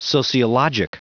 Prononciation du mot sociologic en anglais (fichier audio)
Prononciation du mot : sociologic